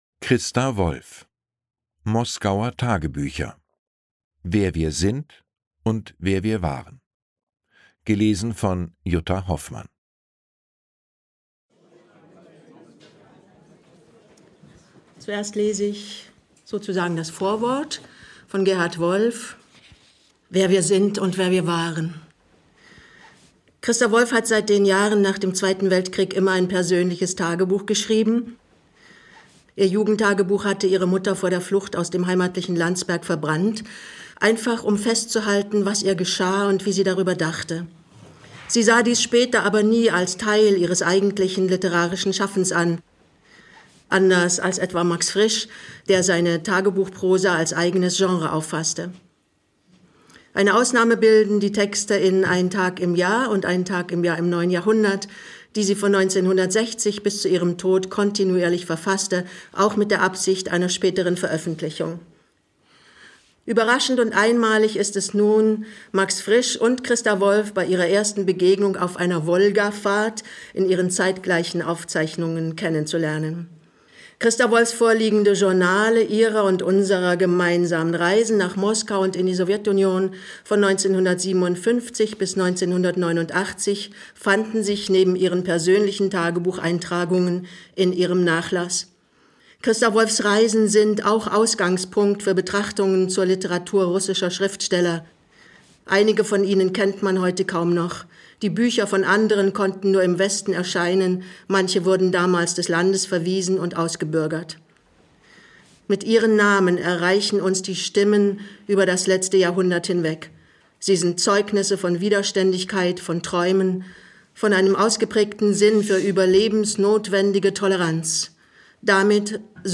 Lesung mit Jutta Hoffmann (1 mp3-CD)
Jutta Hoffmann (Sprecher)